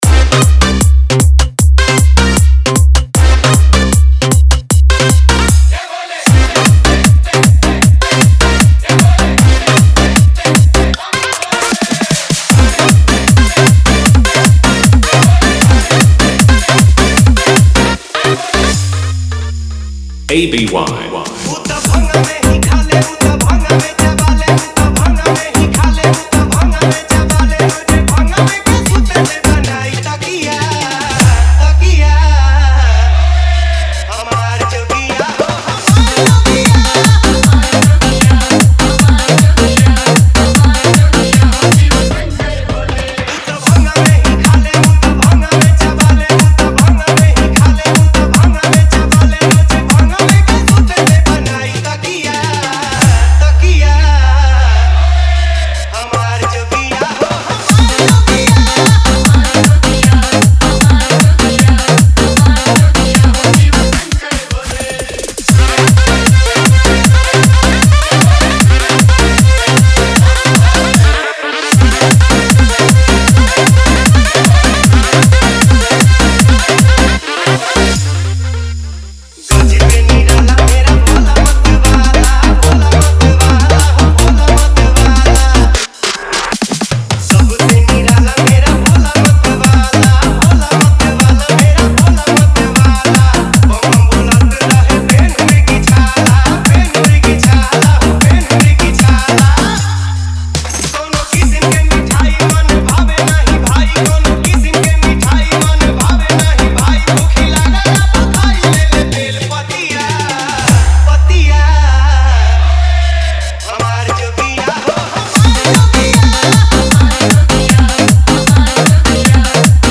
DJ Dialogue Intro Script (Desi Bass Entry)
[Background: हल्की तानपुरा/डमरू बेस FX]
[Bass Boost Drop + Trishul SFX + Vocal Reverb]